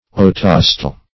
Search Result for " otosteal" : The Collaborative International Dictionary of English v.0.48: Otosteal \O*tos"te*al\, n. [Oto- + Gr.